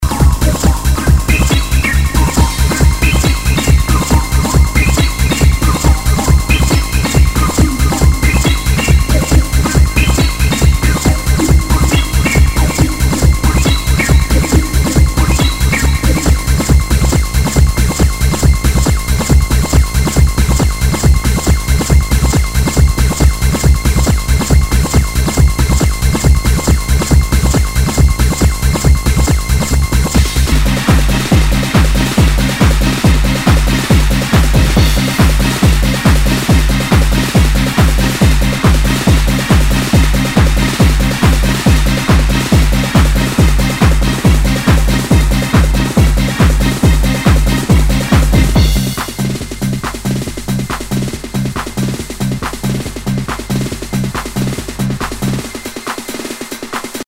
HOUSE/TECHNO/ELECTRO
ナイス！ハード・ハウス / トランス！
全体にチリノイズが入ります